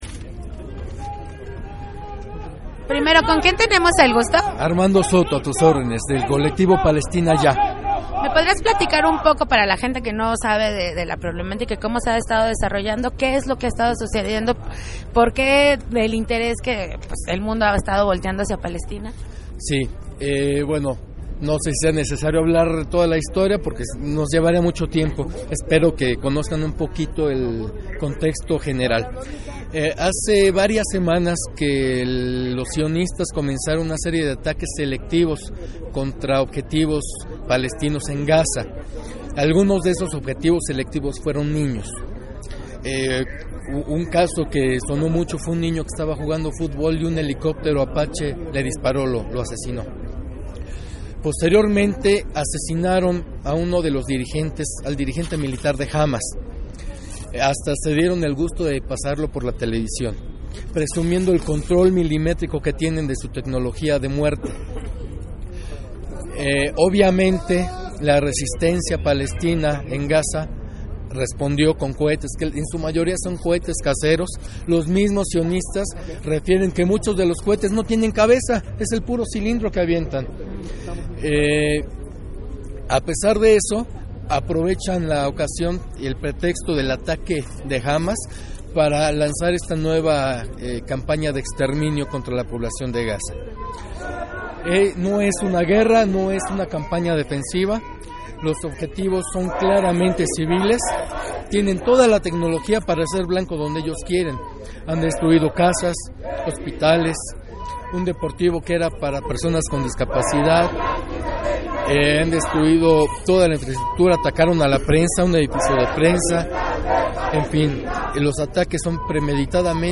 Entrevista Colectivo Palestina Ya